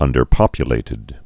(ŭndər-pŏpyə-lātĭd)